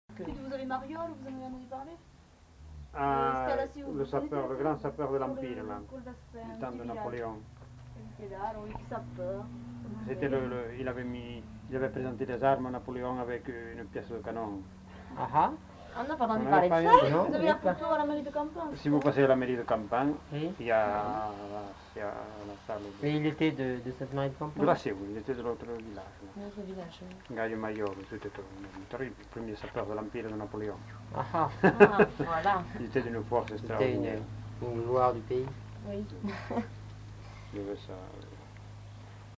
Aire culturelle : Bigorre
Lieu : Campan
Genre : témoignage thématique